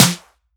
• Subtle Reverb Snare Drum Sample F Key 319.wav
Royality free snare drum sound tuned to the F note. Loudest frequency: 4284Hz
subtle-reverb-snare-drum-sample-f-key-319-anz.wav